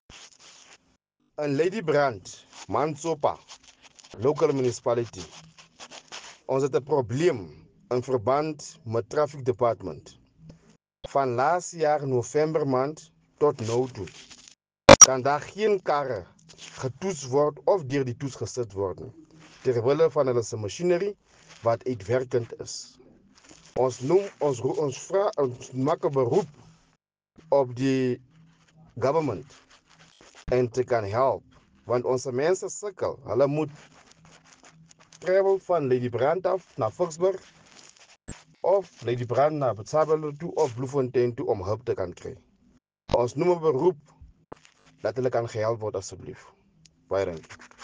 Afrikaans soundbites by Cllr Nicky van Wyk.